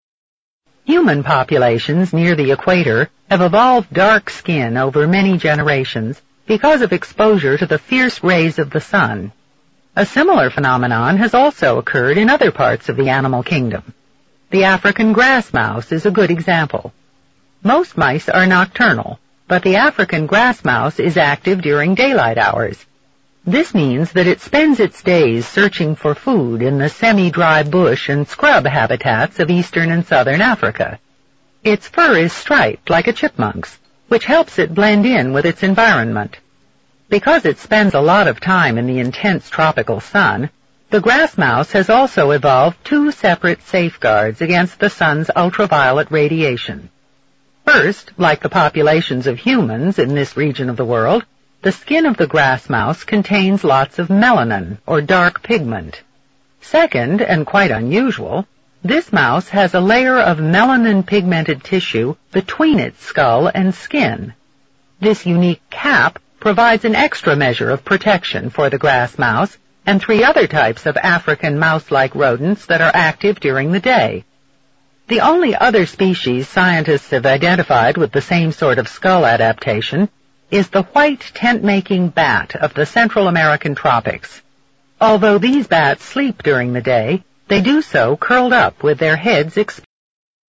You will hear a lecture.